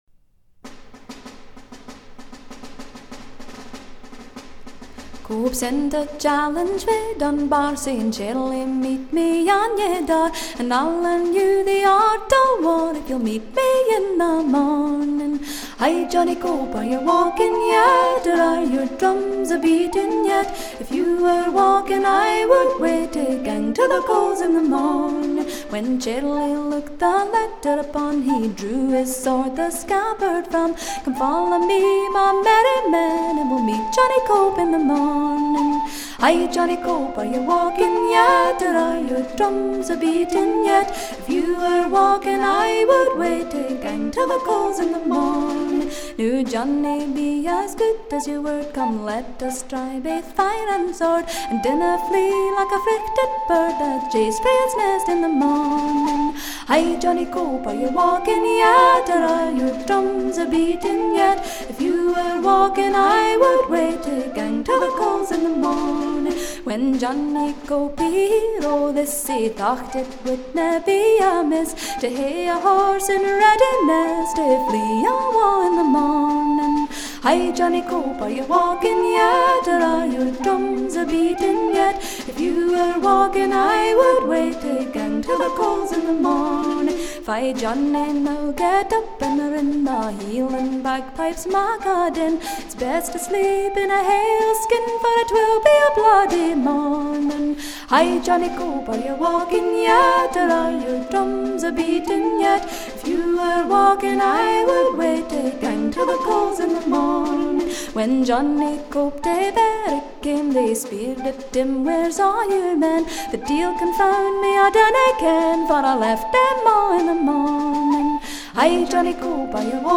朴素的伴奏，纯净的歌声，降服人心。